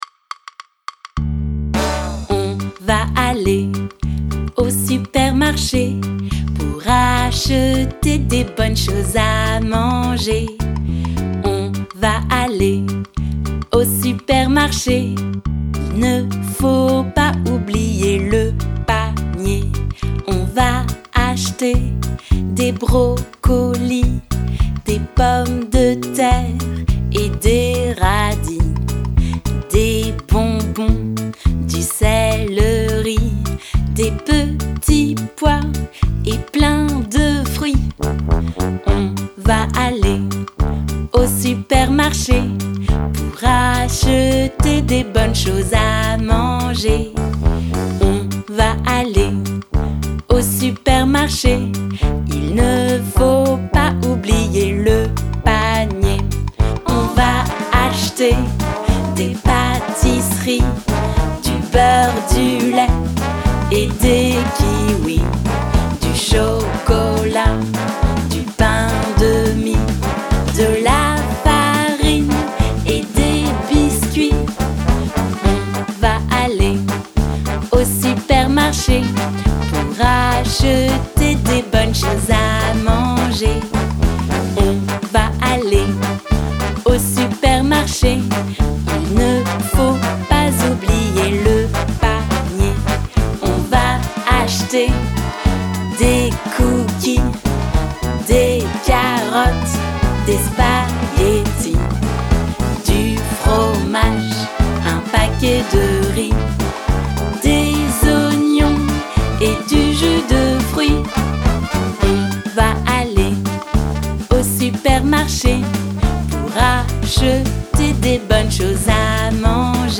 This lively, New Orleans-style song